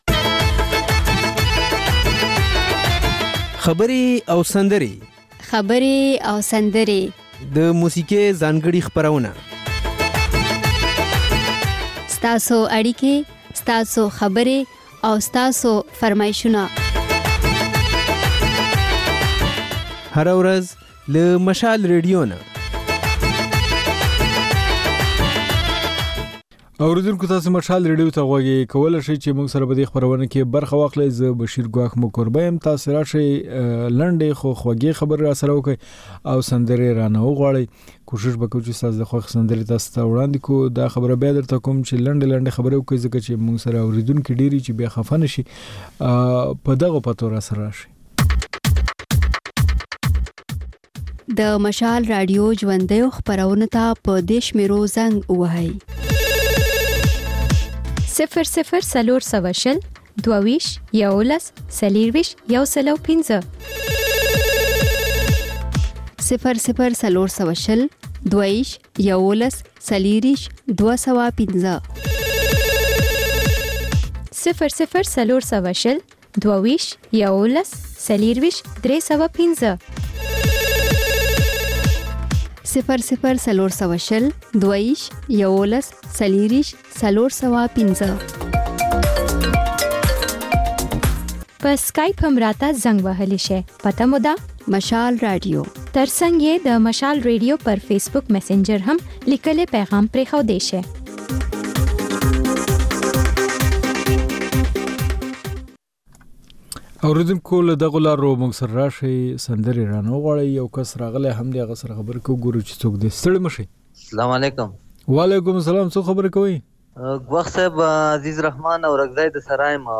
په دې خپرونه کې له اورېدونکو سره خبرې کېږي، د هغوی پیغامونه خپرېږي او د هغوی د سندرو فرمایشونه پوره کېږي. دا یو ساعته خپرونه د پېښور پر وخت سهار پر څلور او د کابل پر درې نیمو بجو تکرار خپرېږي.